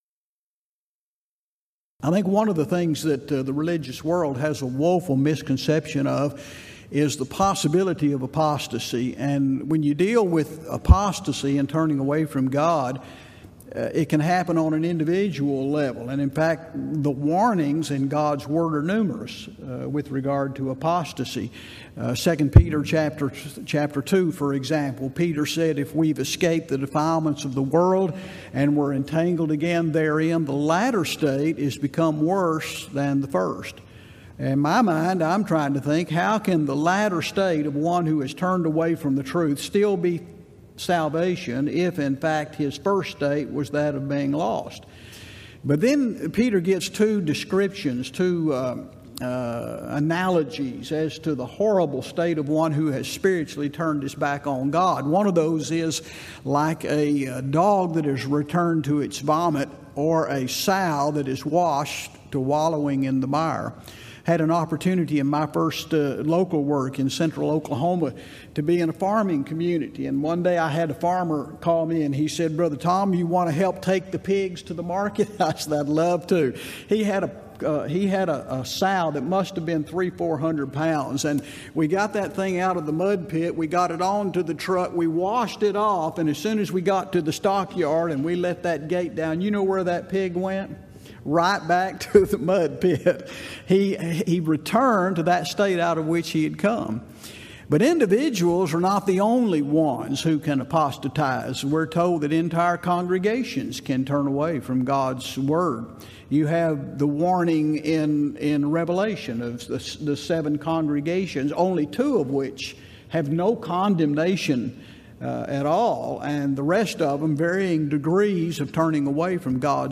Event: 24th Annual Gulf Coast Lectures
lecture